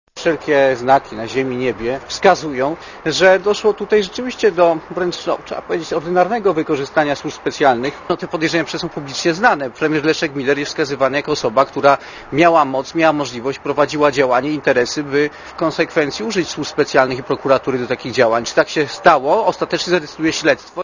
Posłuchaj komentarza Zbigniewa Ziobro